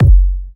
South Philly Deep Kick.wav